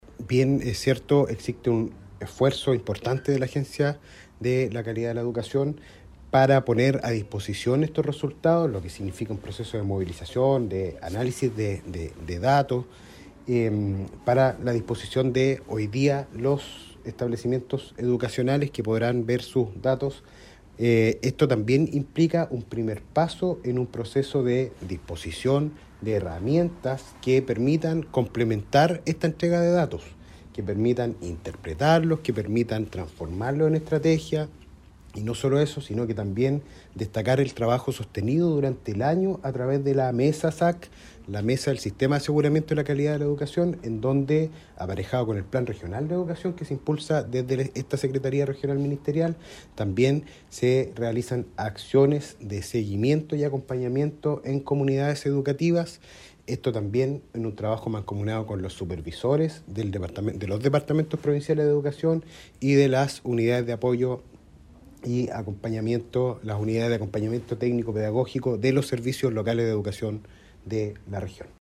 El seremi Benedetti hizo hincapié en los esfuerzos de entregar los resultados en marzo a los establecimientos educacionales, con el fin de corregir problemáticas a tiempo.